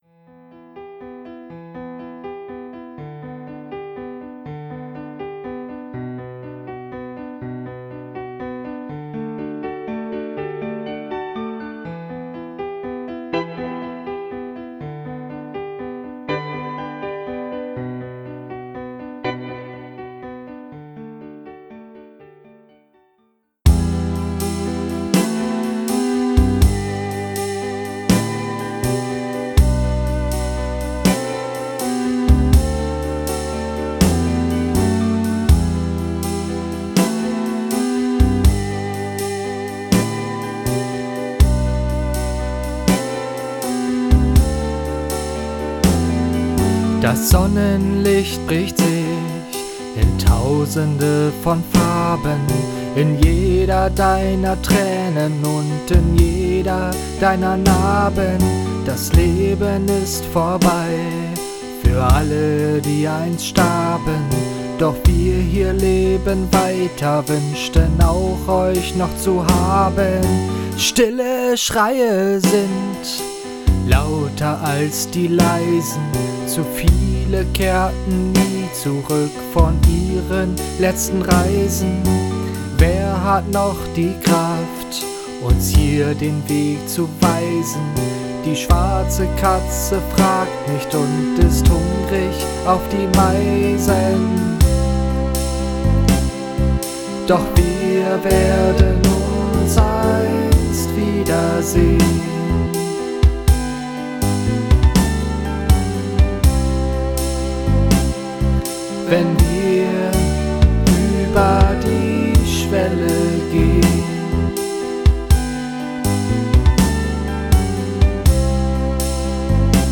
Instruments: Vocals, Guitar, Virtual Instruments